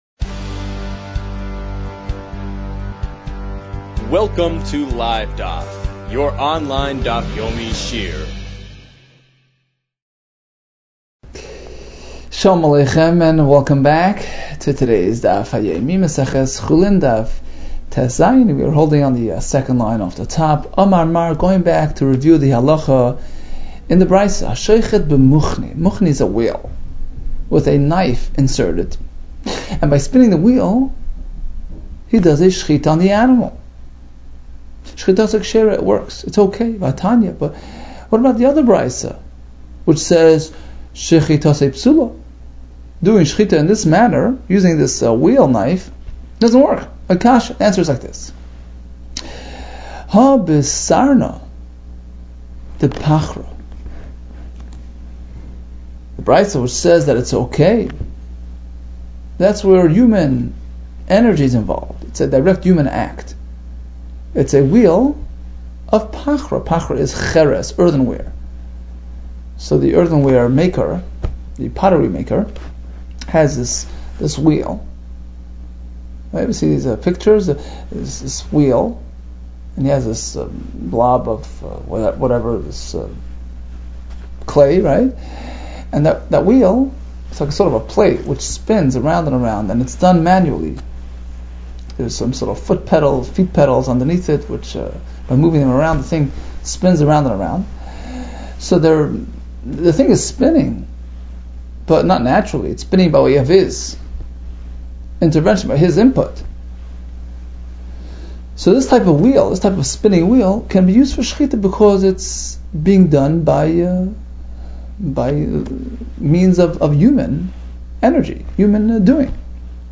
Chulin 16 - חולין טז | Daf Yomi Online Shiur | Livedaf